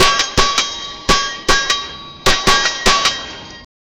Failure music. A hammer hits a machine then the machine breaks.
failure-music-a-hammer-hi-ce6nc4ak.wav